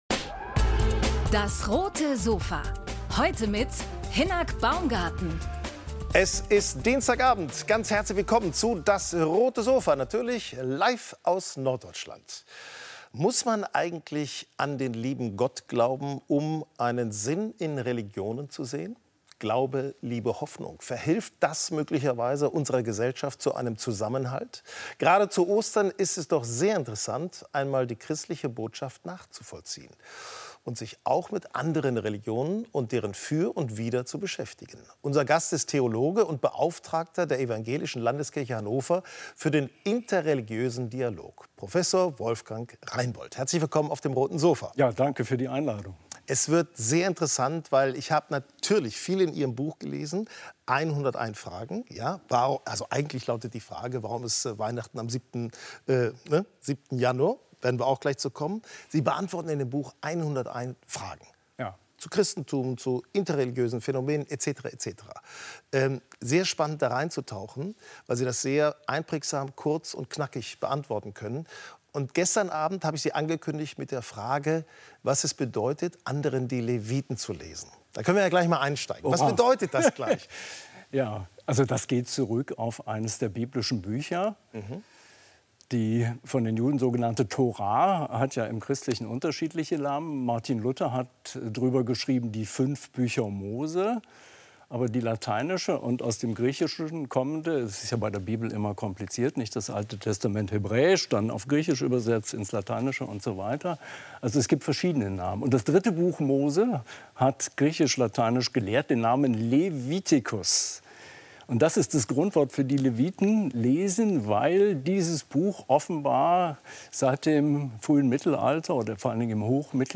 im Gespräch über die Vielfalt der Religionen ~ DAS! - täglich ein Interview Podcast